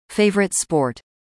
26. favorite sport   /ˈfeɪ.vər.ət//spɔːrt/ : môn thể thao yêu thích